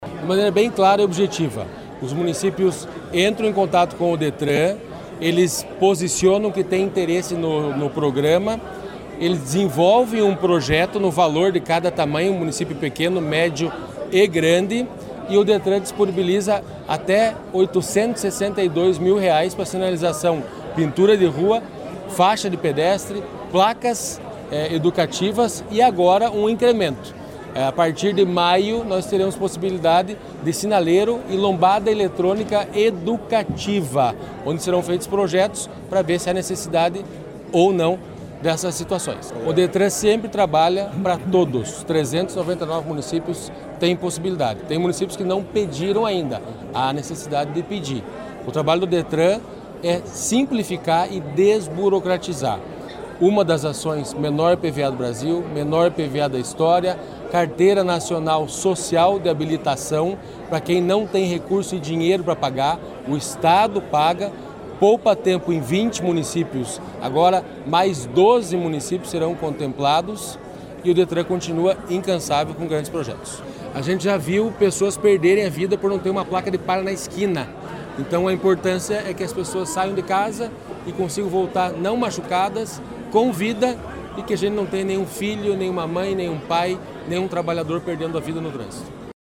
Sonora do diretor-presidente do Detran-PR, Santin Roveda, sobre os anúncios do programa Sinaliza Paraná | Governo do Estado do Paraná